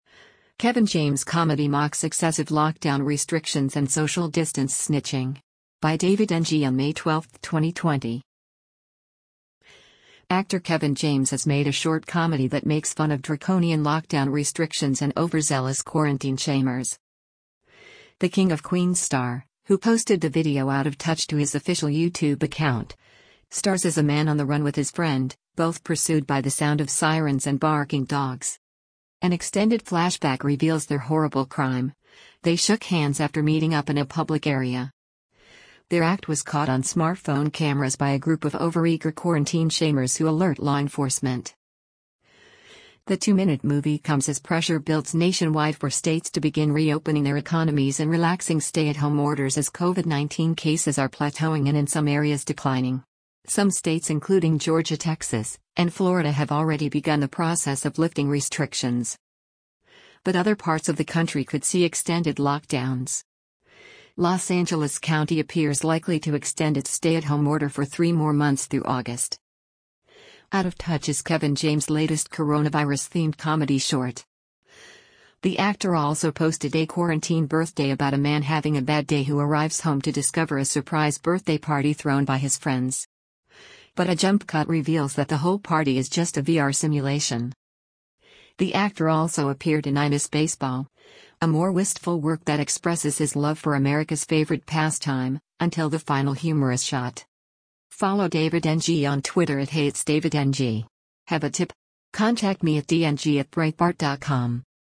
The King of Queens star, who posted the video Out of Touch to his official YouTube account, stars as a man on the run with his friend, both pursued by the sound of sirens and barking dogs.